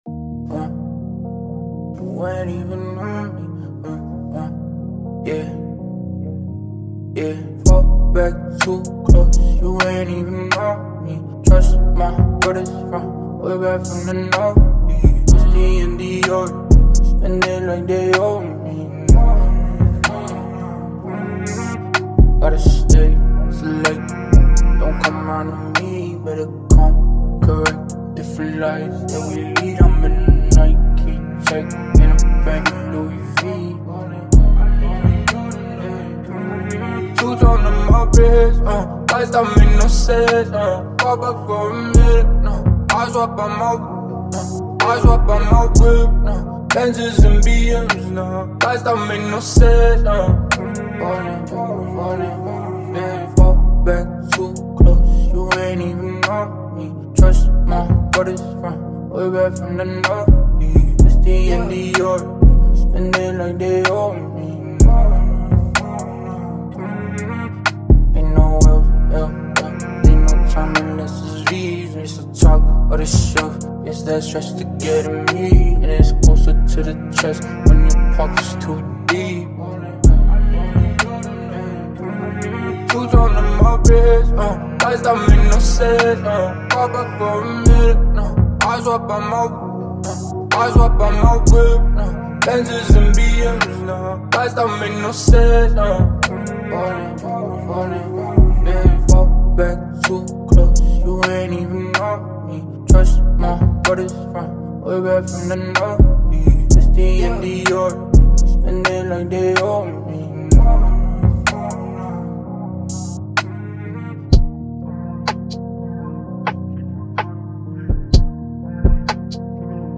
• Жанр: Hip-Hop, Rap